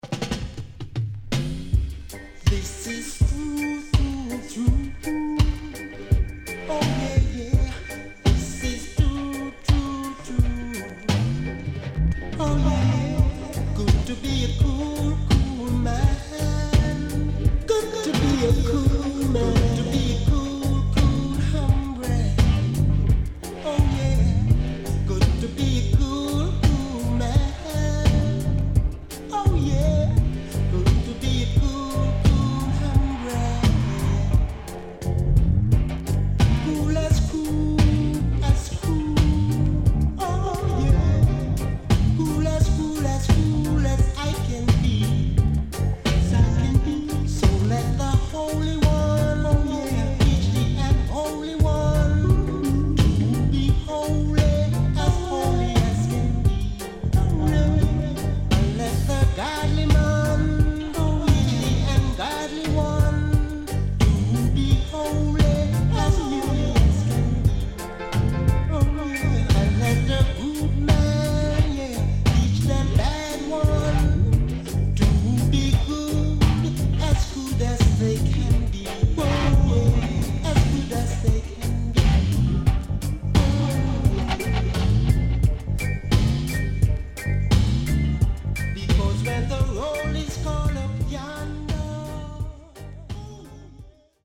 HOME > Back Order [VINTAGE LP]  >  KILLER & DEEP